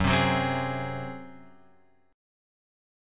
F#sus2b5 chord {2 3 4 5 x 4} chord